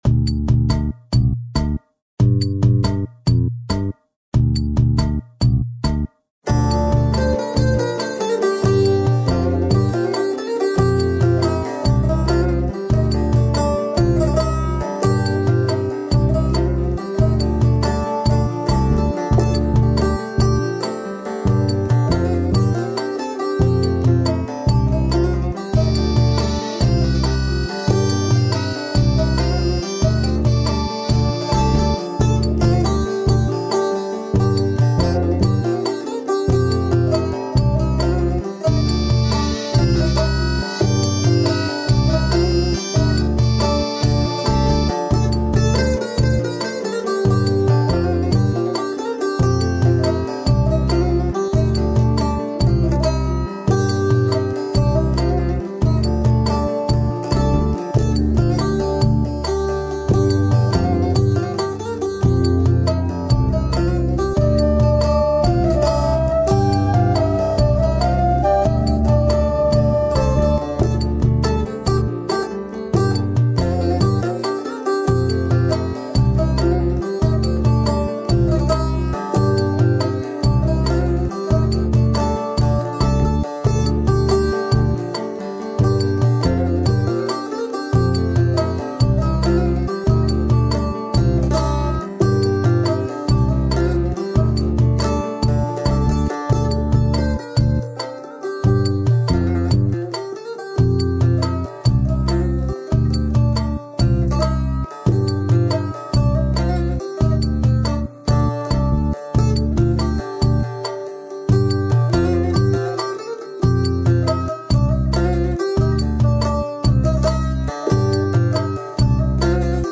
Sigaramı Yandurdum Enstrumantal